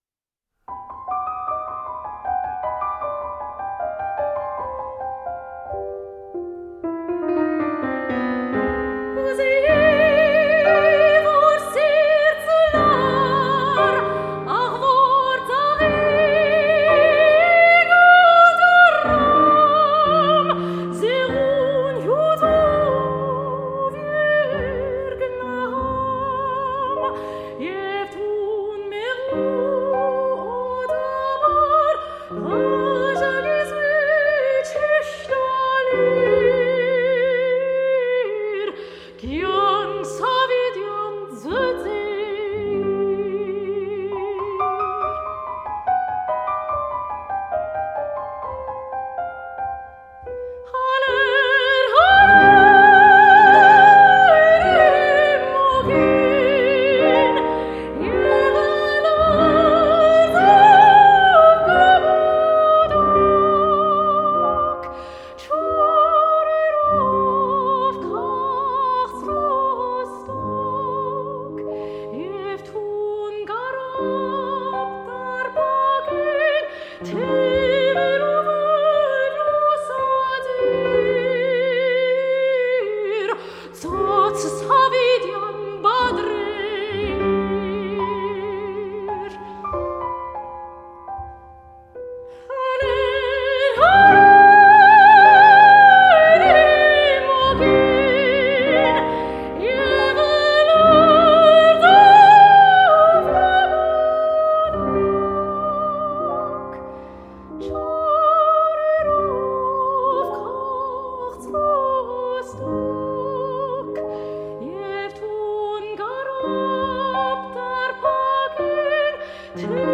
Armenian Art Songs III.
soprano